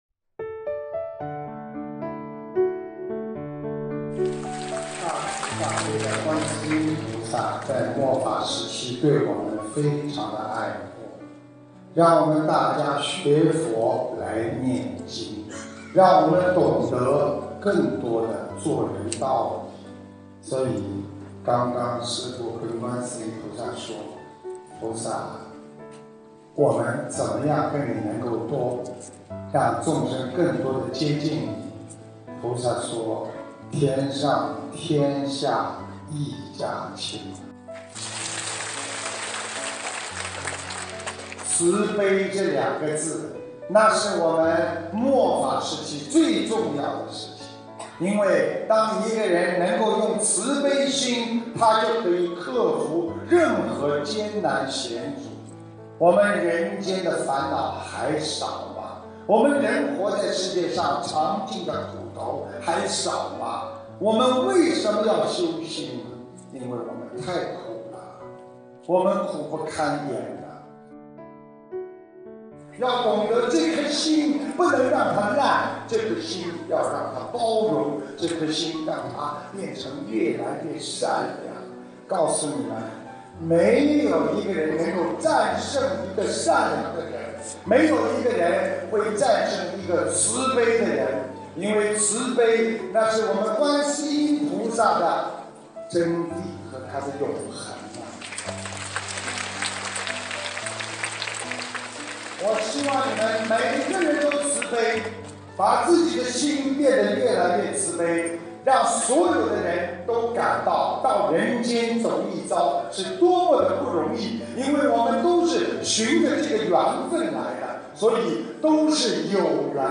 音频：五浊恶世中永不后退的法宝_2015年1月17日马来西亚·沙巴观音堂开光开示!